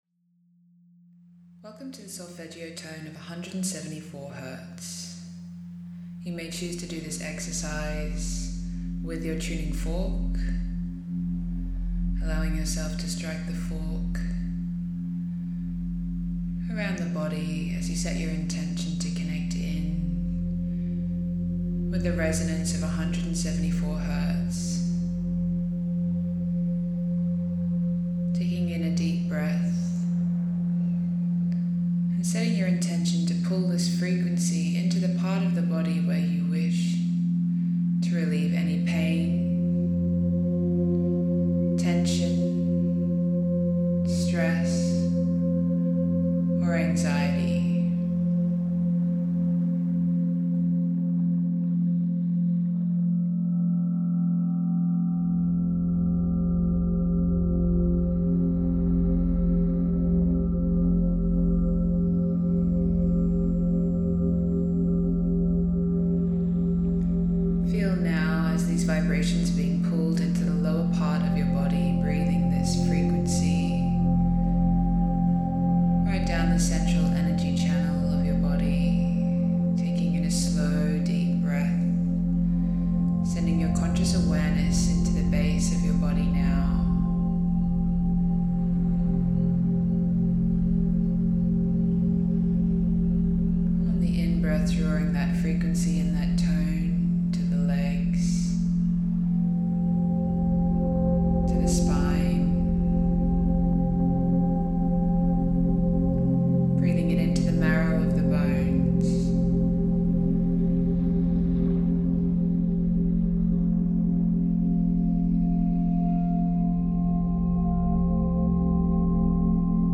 As the lowest Solfeggio tone, it’s the start of healing your physical and emotional aura.
174hz with your Tuning Fork meditation - Vibrosound Healing.mp3